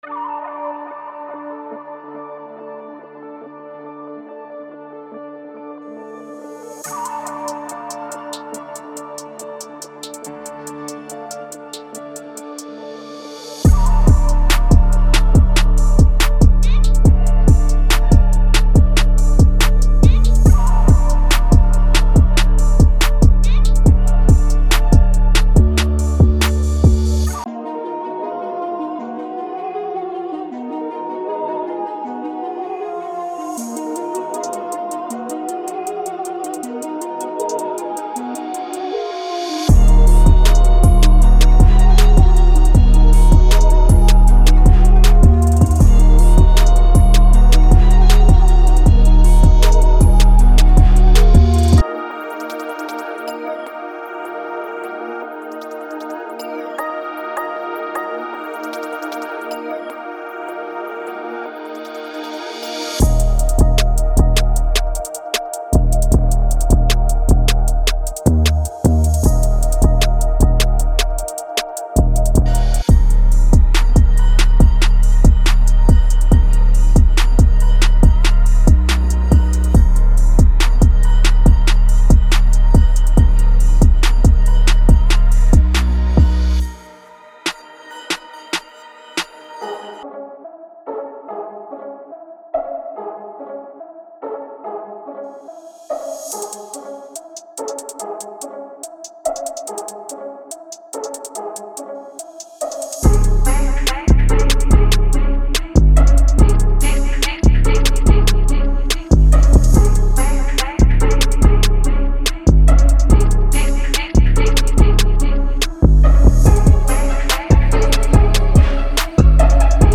均衡的音色选择，使其能够完美融入任何混音。动人的钢琴旋律能够激发您的创作灵感。